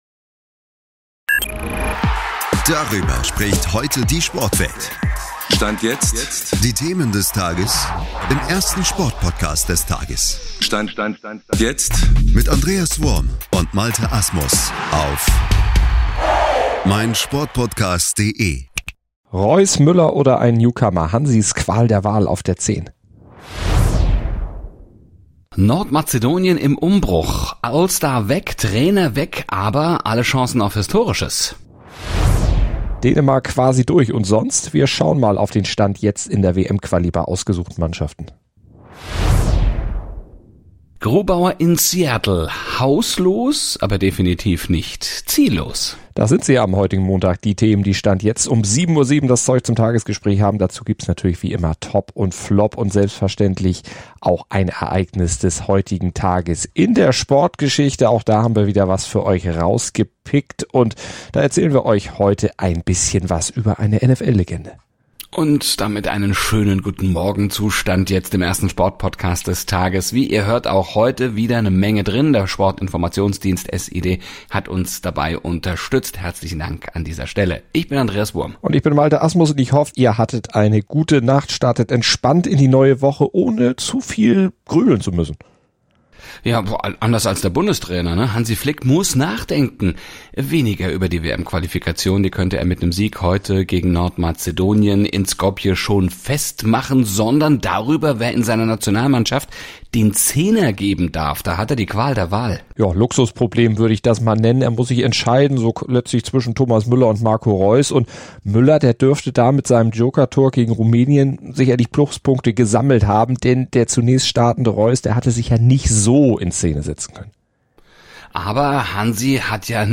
Wir schauen auf den Stand jetzt in der WM-Quali Interview: Grubauer in Seattle …